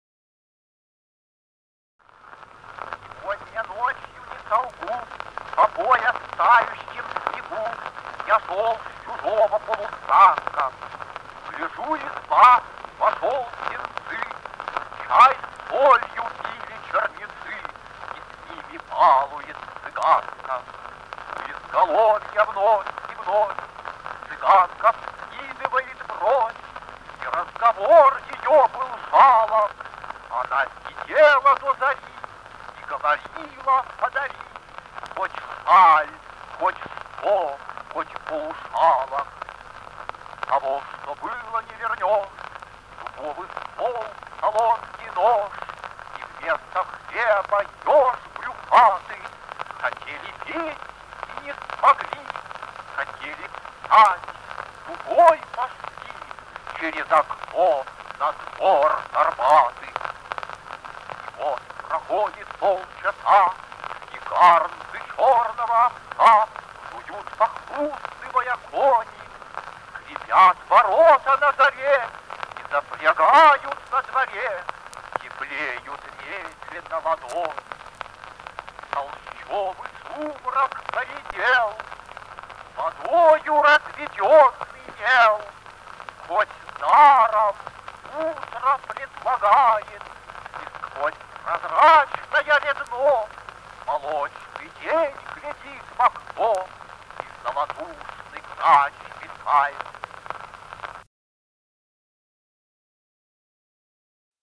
(Голоса авторов) Осип Мандельштам